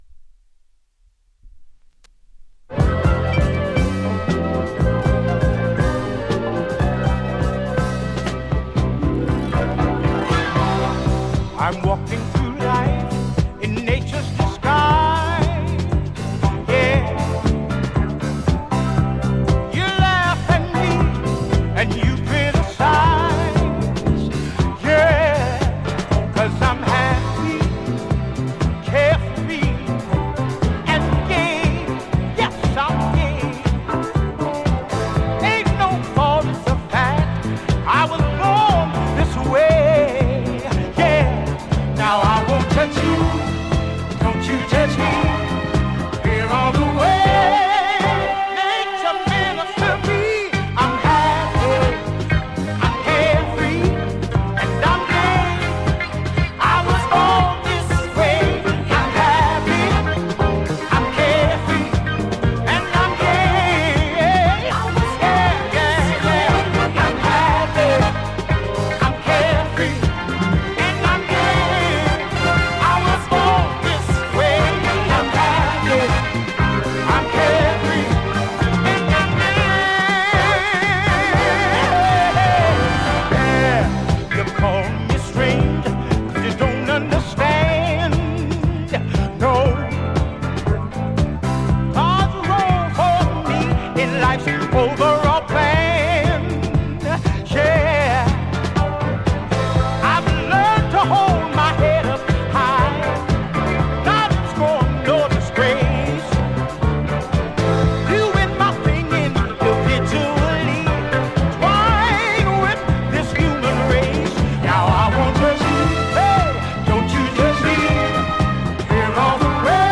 ジャンル(スタイル) DISCO / GARAGE / DANCE CLASSIC / REISSUE